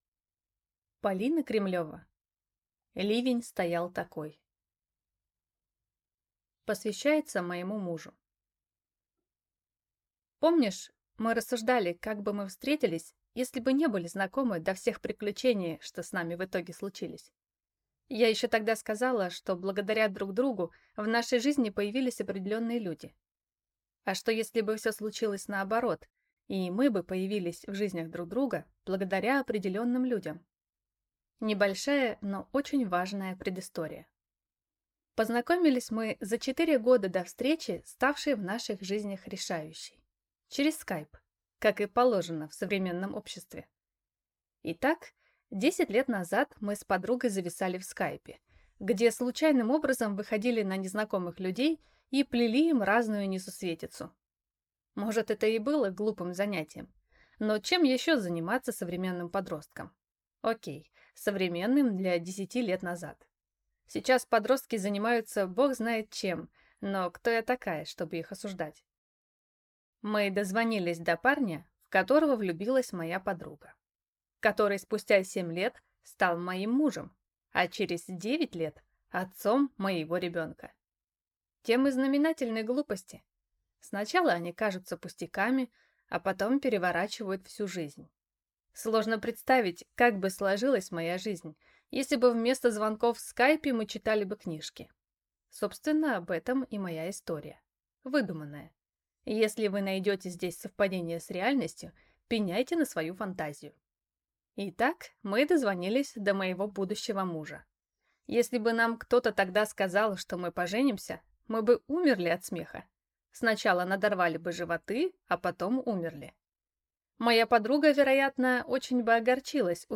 Аудиокнига Ливень стоял такой | Библиотека аудиокниг